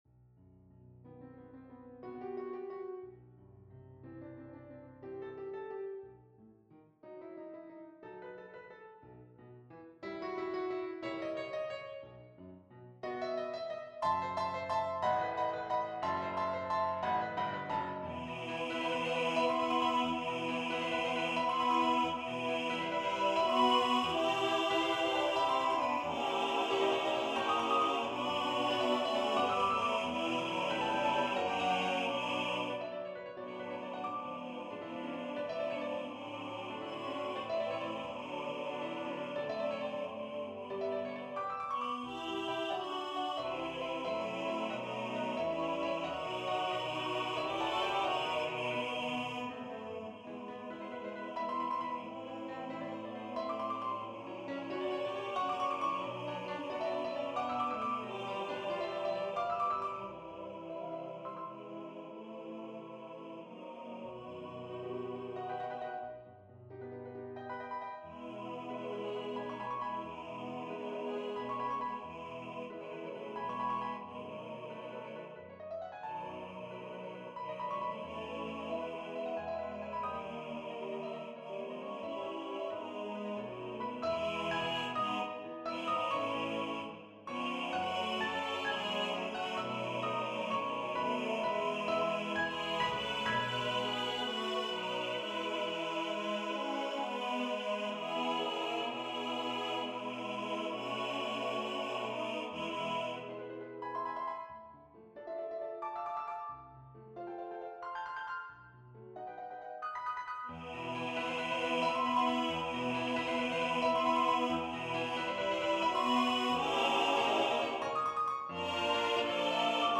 Voices: T1, T2, B1, B2 Instrumentation: Piano
NotePerformer 4 mp3 Download/Play Audio